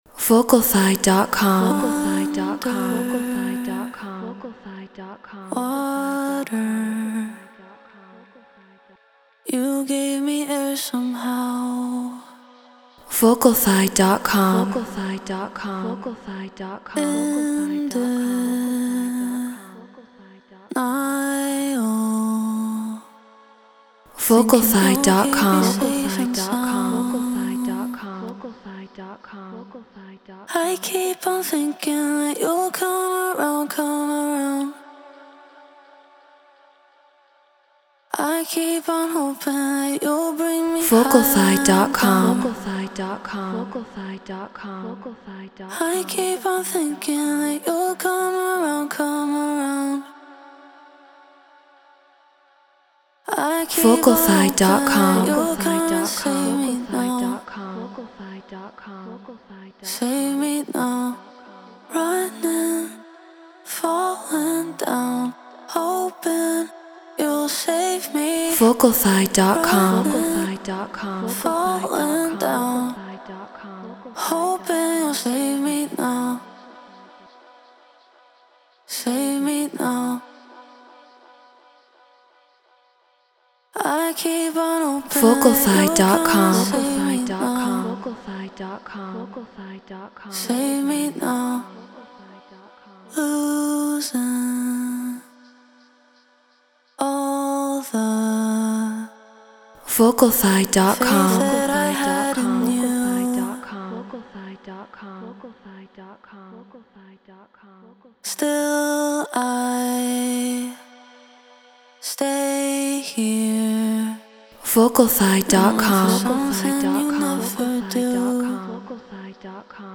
House 126 BPM F#min
Shure SM7B Apollo Solo Logic Pro Treated Room